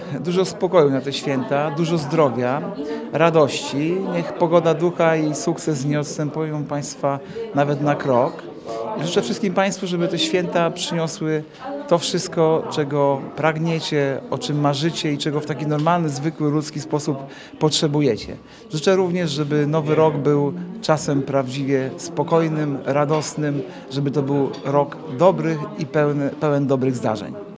Już po raz XVIII w ełckim studiu Radia 5 gościliśmy przedstawicieli władz miasta, służb mundurowych, lokalnych firm i instytucji.
Miejskich samorządowców na spotkaniu opłatkowym reprezentował Artur Urbański, zastępca prezydenta Ełku.